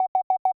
BEEP_Targeting Loop_06.wav